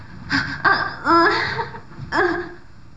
「色っぽい声」の秘密
voice.wav